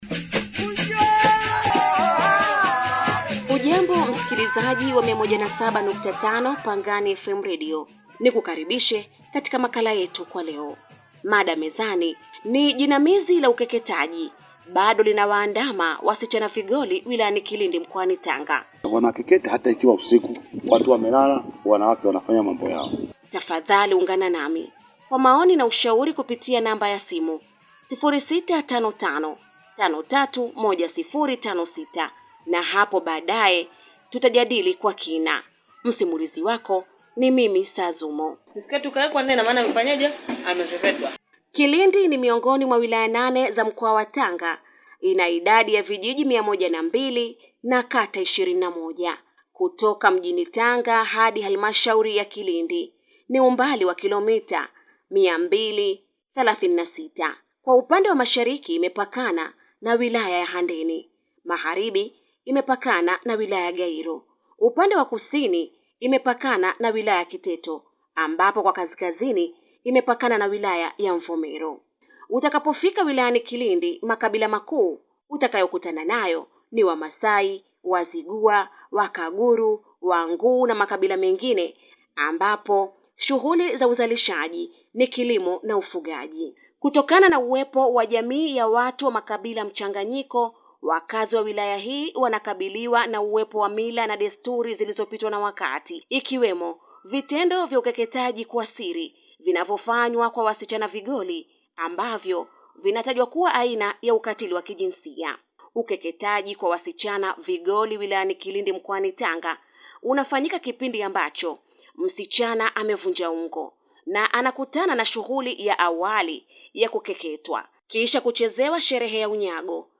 makala-jinamizi-la-ukeketaji-bado-linawaandama-wasichana-vigoli-wilayani-kilindi.mp3